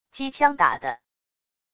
机枪打的wav音频